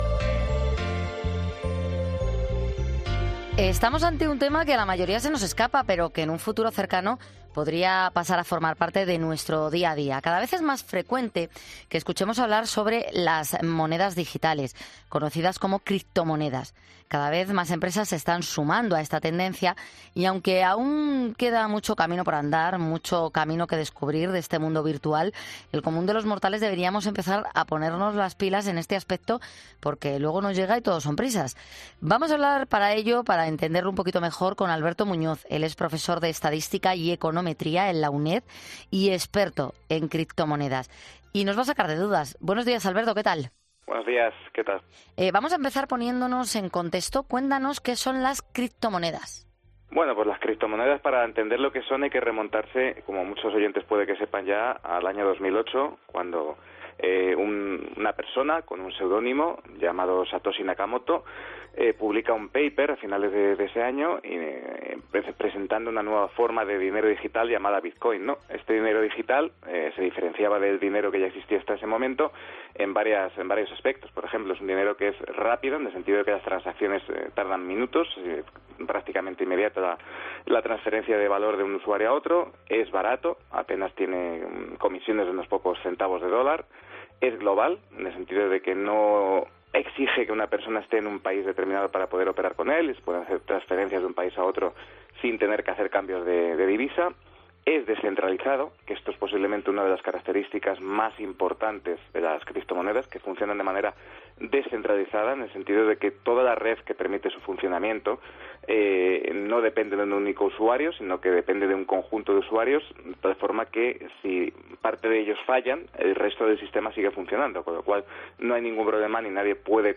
El tema de esta entrevista parece sacado de una película de ciencia ficción y es que parece que avanzamos hacia el futuro a pasos agigantados.